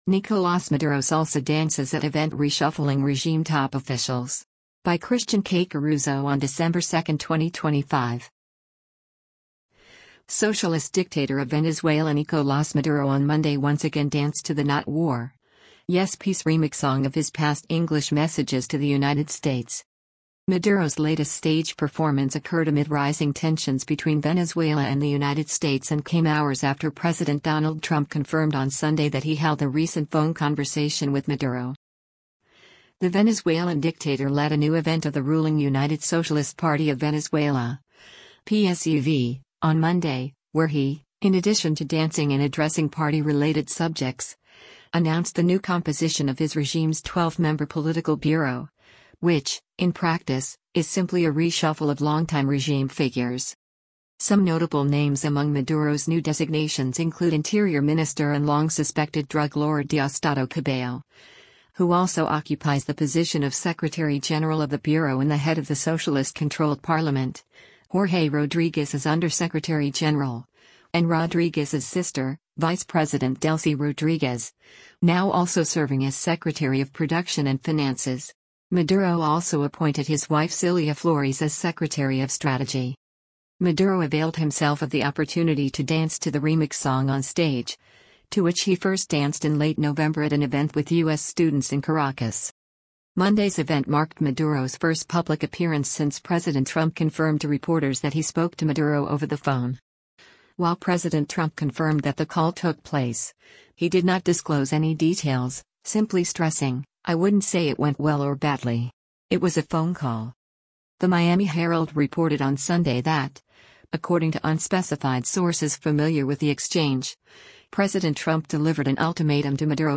Socialist dictator of Venezuela Nicolás Maduro on Monday once again danced to the “Not War, Yes Peace” remix song of his past “English” messages to the United States.